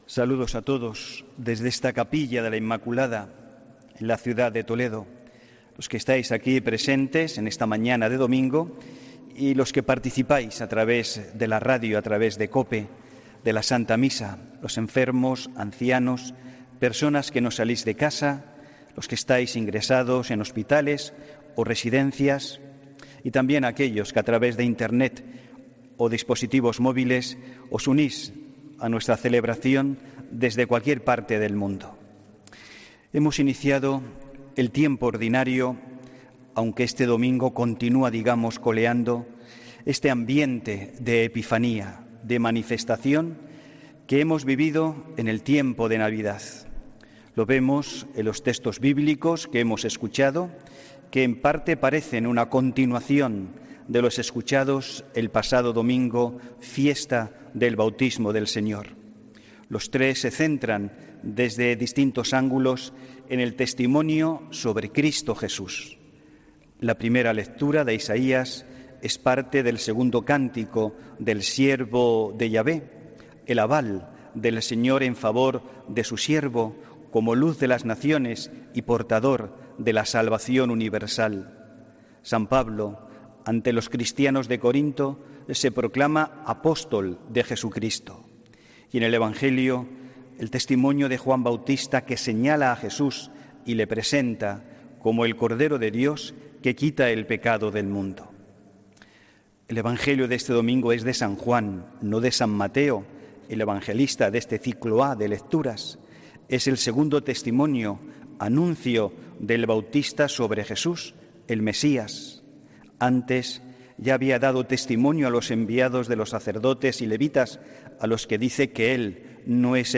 HOMILÍA 19 ENERO 2020